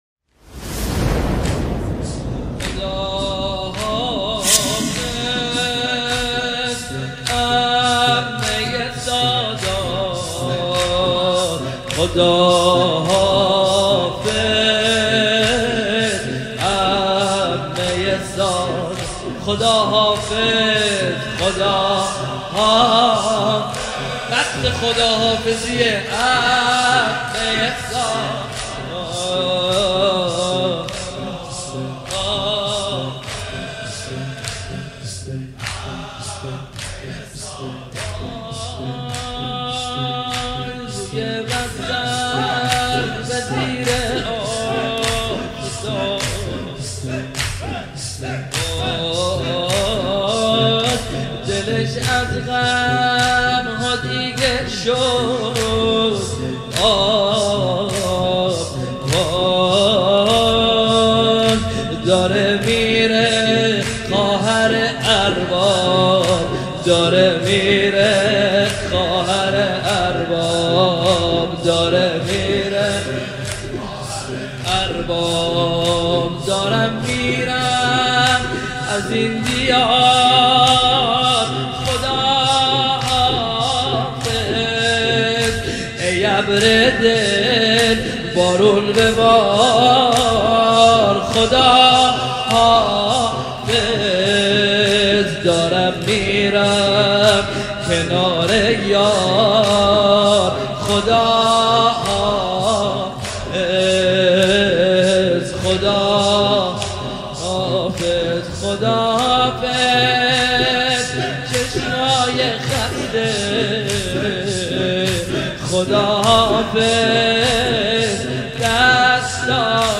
مداحی
وفات حضرت زینب(س)